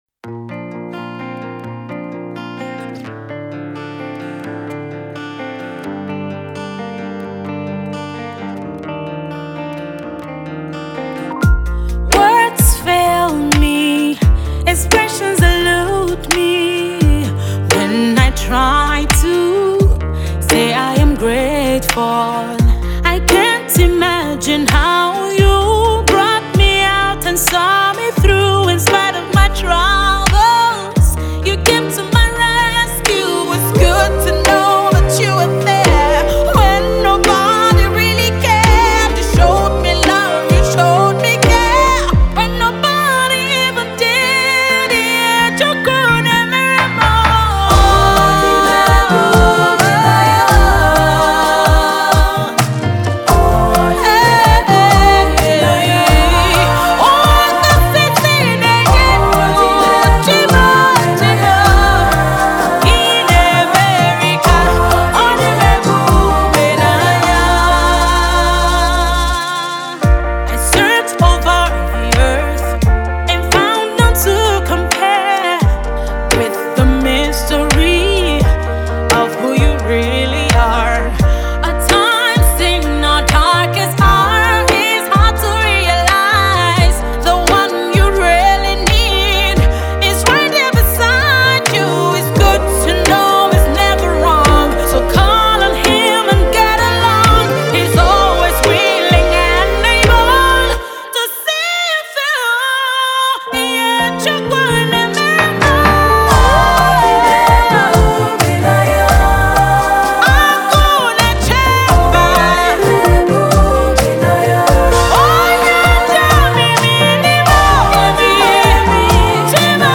Emerging Gospel Artist and Versatile Singer
powerhouse vocals
It features a fine blend of jazz, pop and African rhythm.